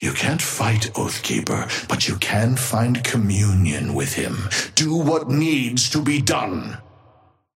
Amber Hand voice line - You can't fight Oathkeeper, but you can find communion with him.
Patron_male_ally_ghost_oathkeeper_5g_start_03.mp3